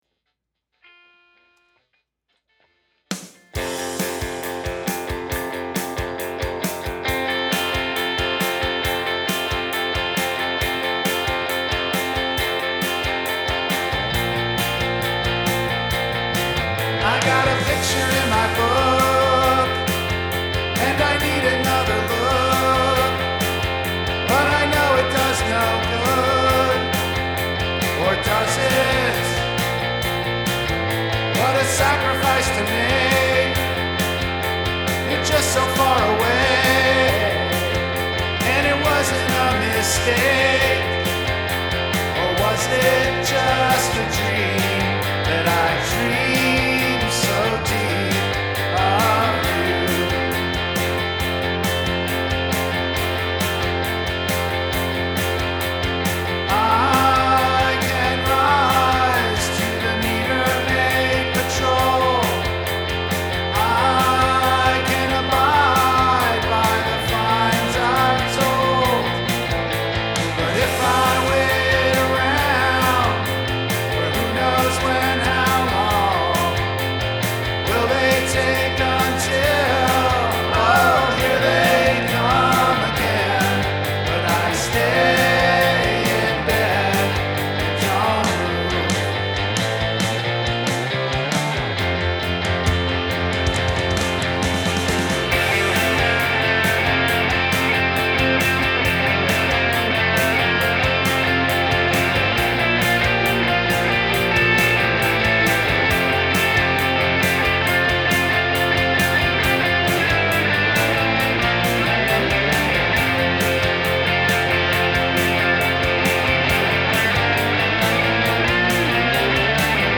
Here is the original version.